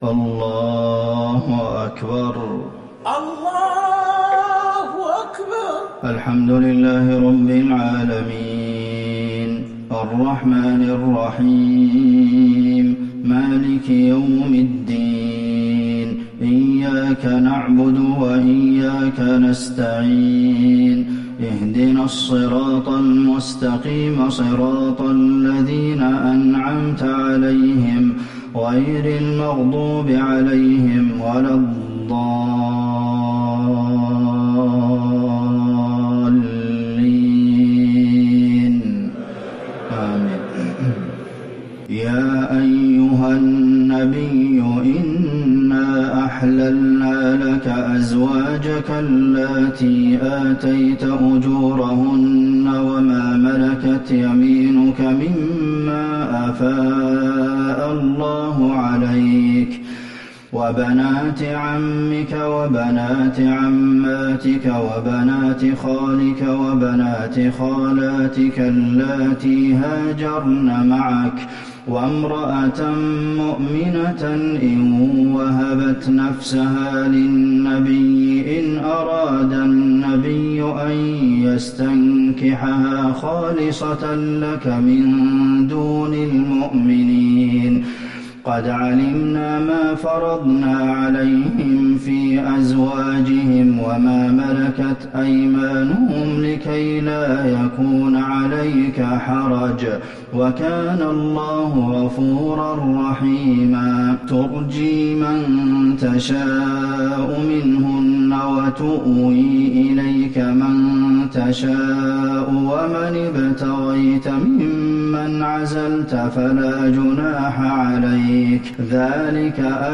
صلاة العشاء للشيخ عبدالمحسن القاسم 5 جمادي الآخر 1441 هـ
تِلَاوَات الْحَرَمَيْن .